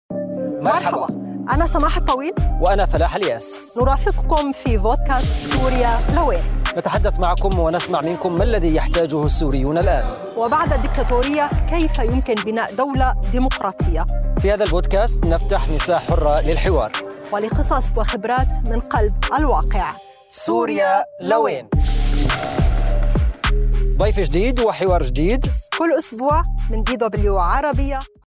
بودكاست "سوريا.. لوين؟" حوار أسبوعي مع شخصيات مؤثرة داخل سوريا وخارجها.